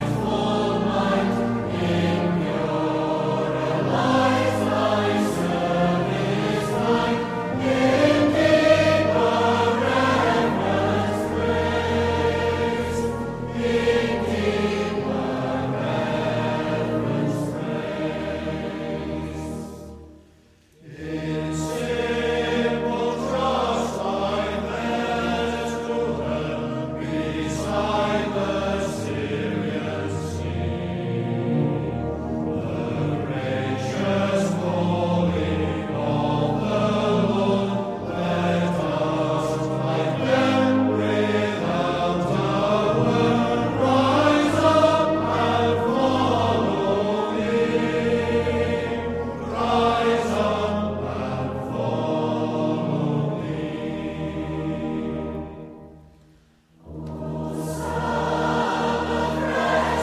Praise & Worship